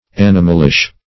Animalish \An"i*mal*ish\, a. Like an animal.
animalish.mp3